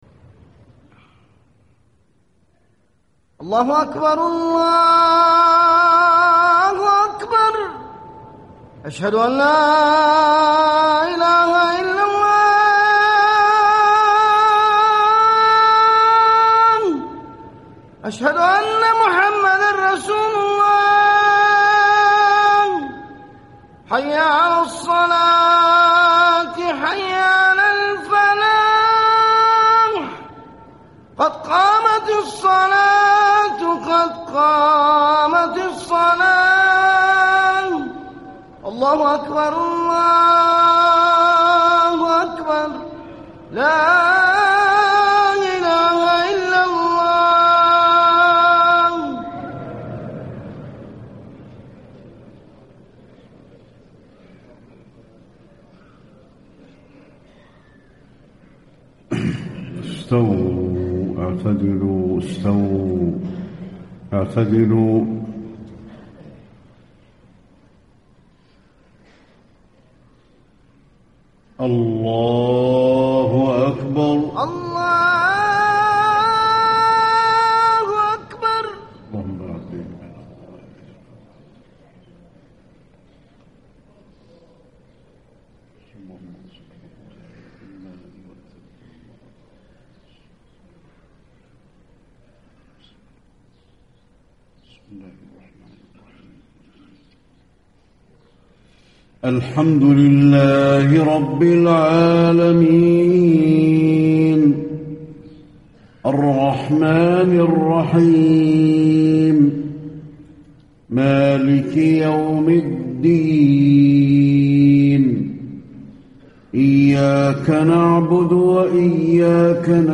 صلاة الفجر8-6- 1435 سورة الحاقة > 1435 🕌 > الفروض - تلاوات الحرمين